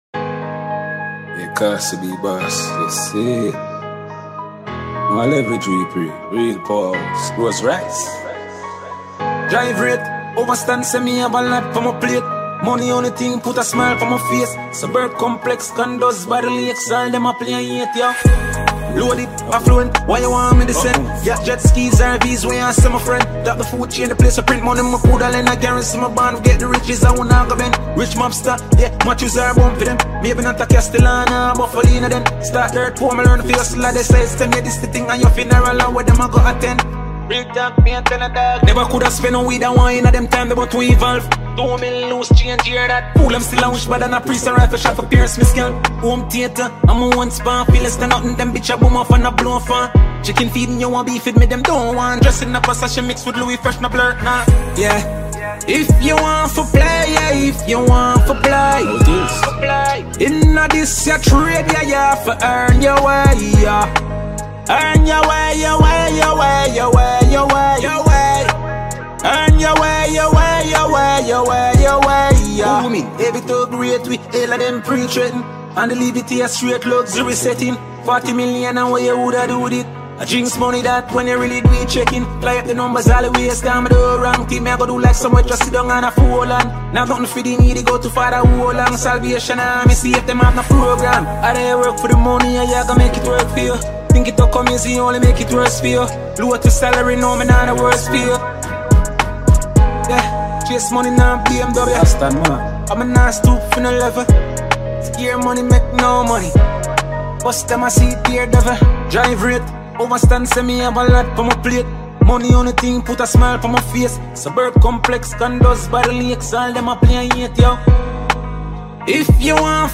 a free Mp3 2025 dancehall music for download.